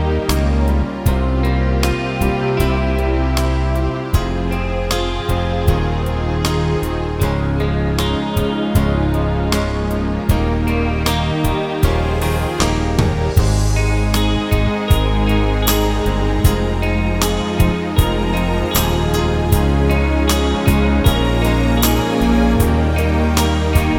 no Backing Vocals Soul / Motown 4:07 Buy £1.50